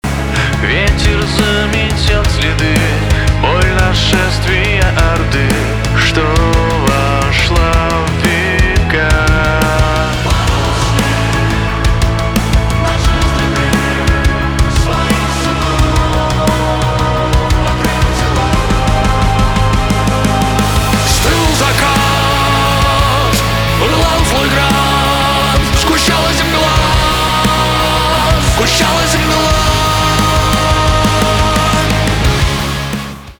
русский рок , эпичные
гитара , барабаны